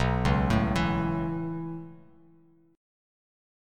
B7sus2sus4 chord